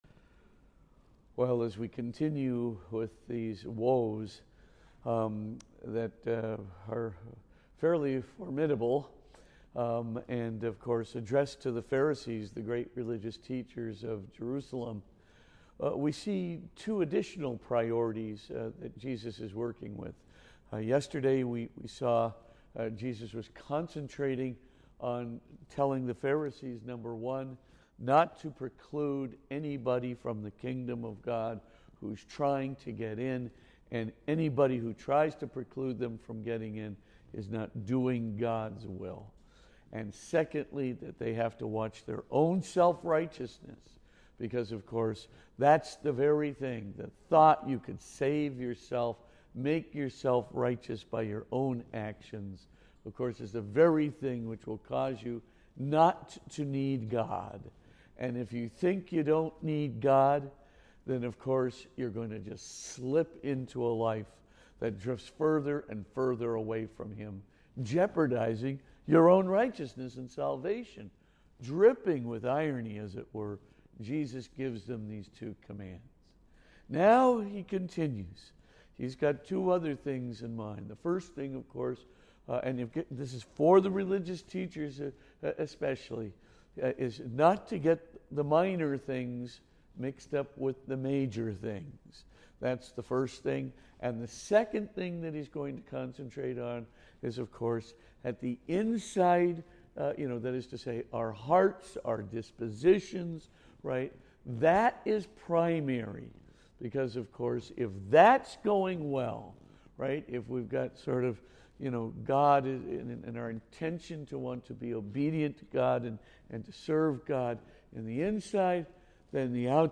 Podcast (fr_spitzer_homilies): Play in new window | Download